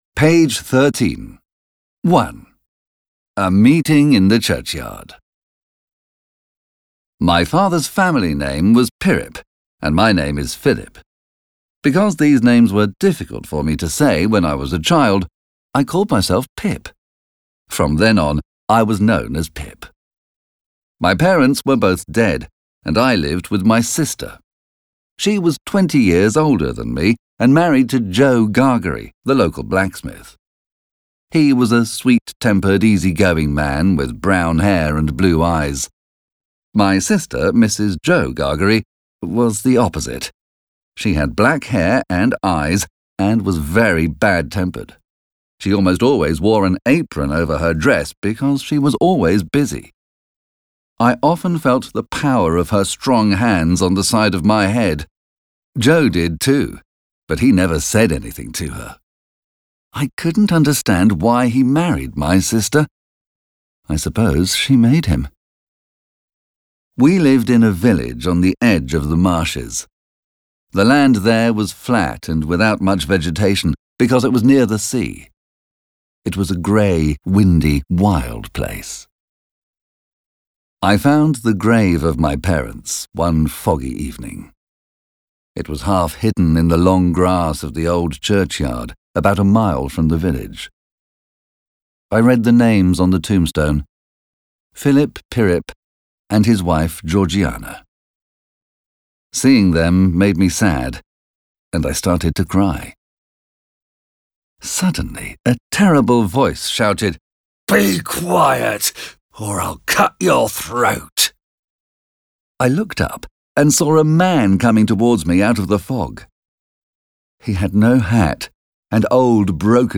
★ 本書錄音採用英式發音
全系列皆隨書附贈全文朗讀MP3。